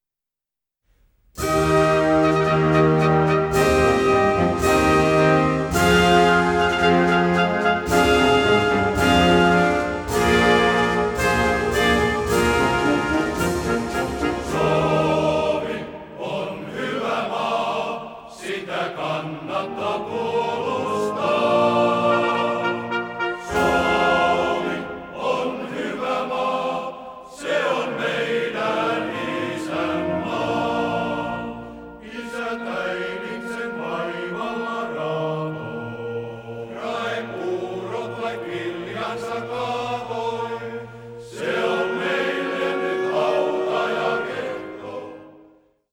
Isänmaallisia lauluja